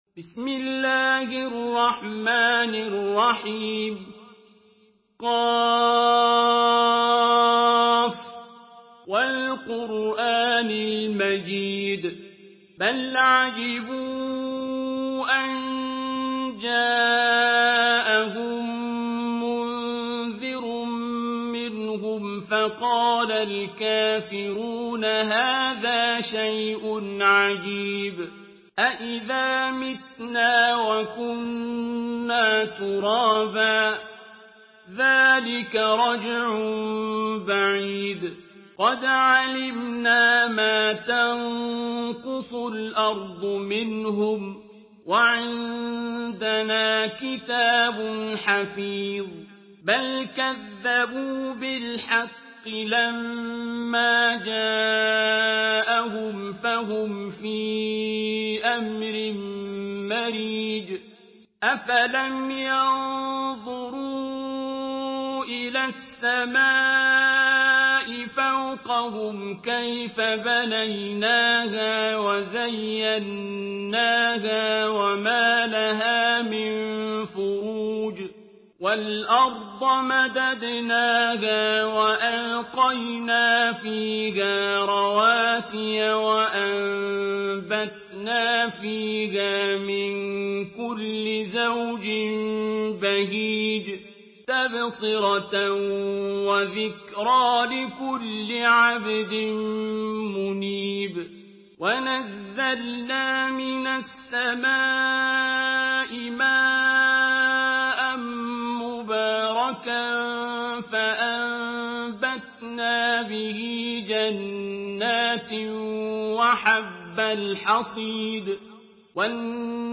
Surah Kaf MP3 by Abdul Basit Abd Alsamad in Hafs An Asim narration.
Murattal Hafs An Asim